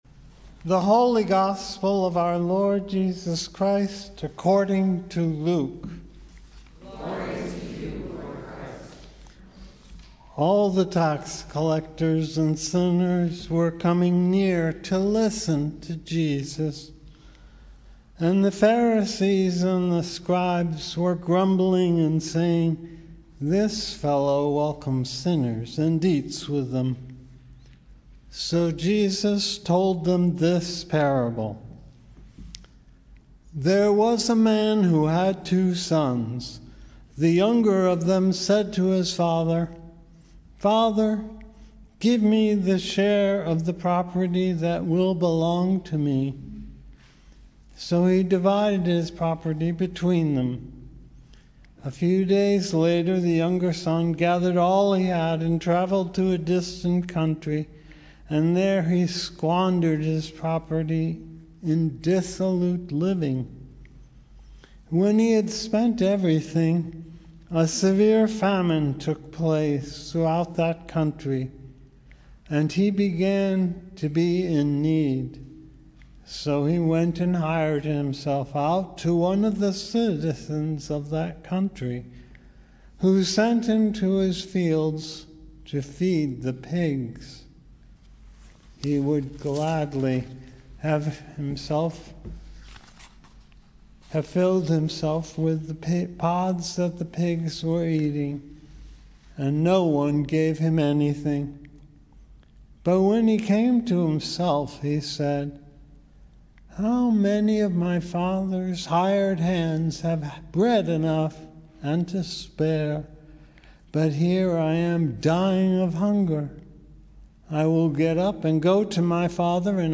Gospel & Sermon, March 27, 2022 - St. Andrew's Episcopal Church